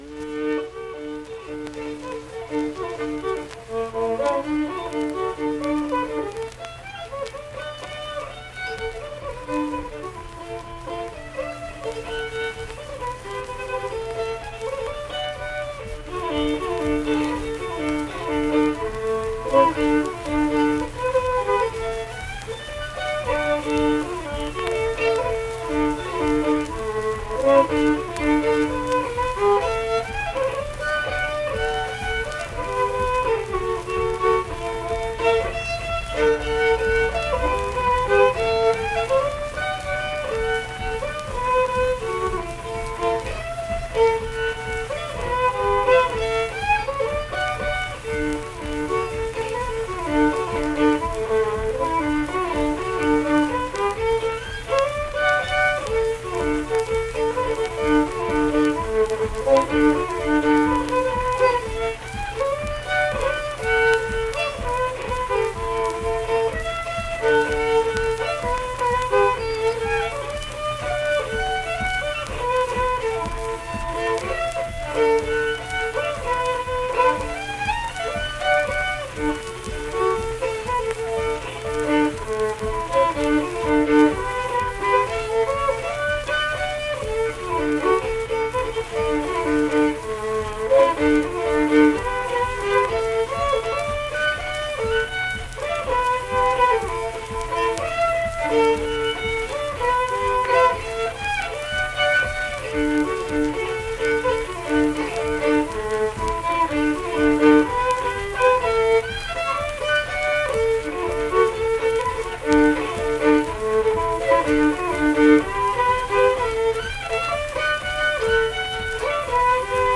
Unaccompanied fiddle performance
Instrumental Music
Fiddle
Tyler County (W. Va.), Middlebourne (W. Va.)